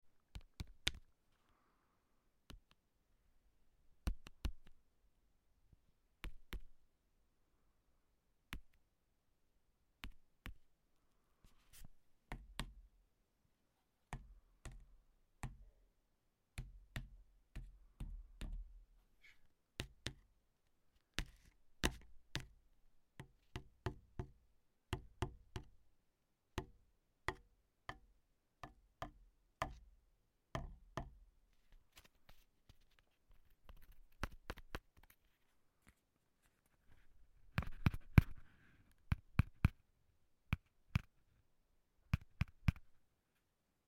Звуки марихуаны
Шорох забивки травки в сигарету